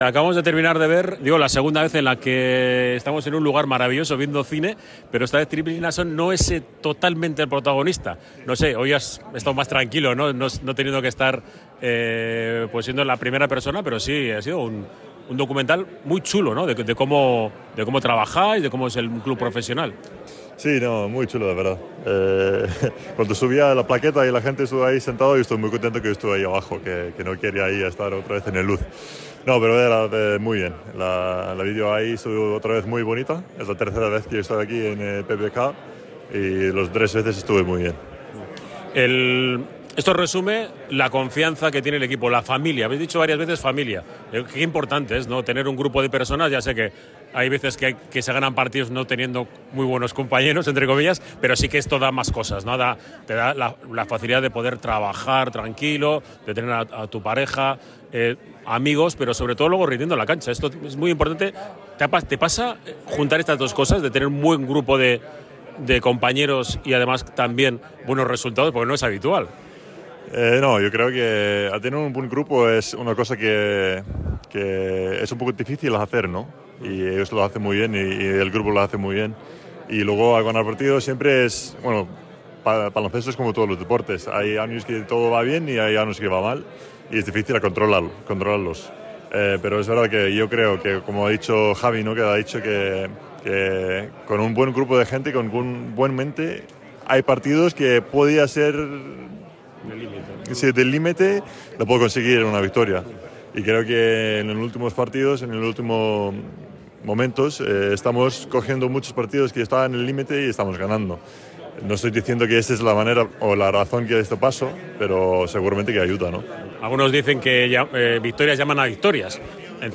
El pívot del Surne Bilbao, Tryggvi Hlinason, ha pasado por los micrófonos de Oye cómo va Dark Edition en Radio Popular apenas unos minutos después del preestreno del documental del equipo en la Sala BBK.